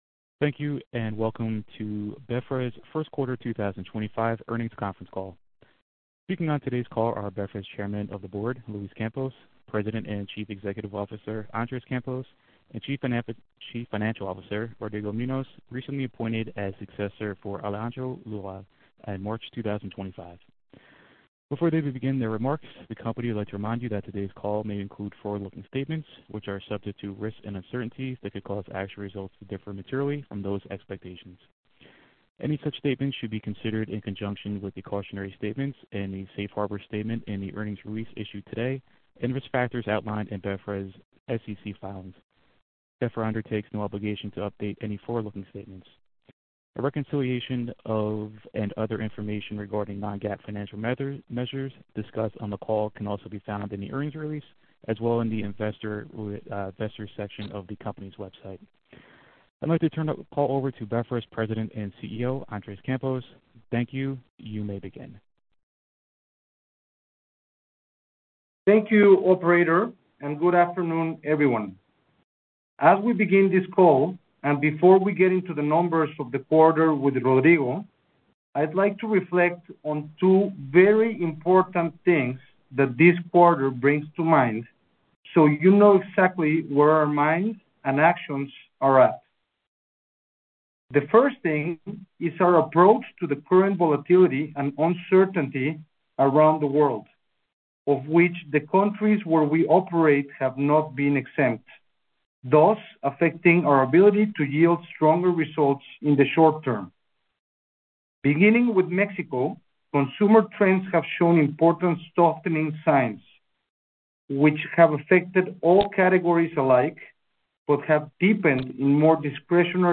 First Quarter 2025 Earnings Conference Call